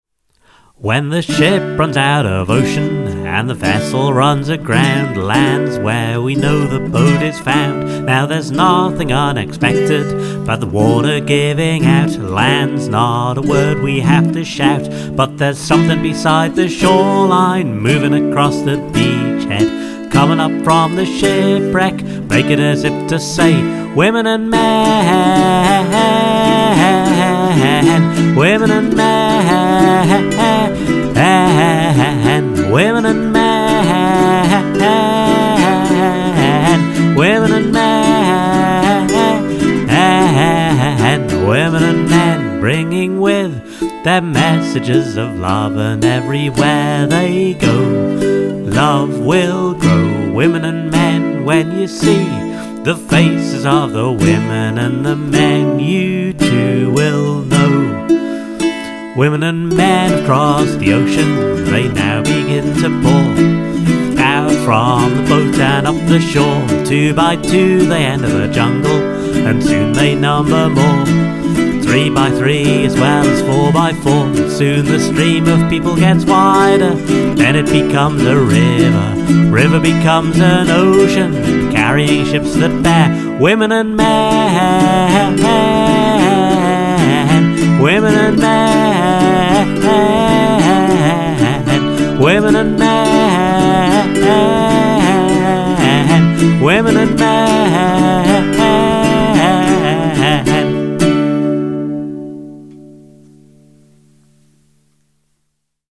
A reassuringly simple four chord folk song this time around.
Really satisfying cover on a rollicking song.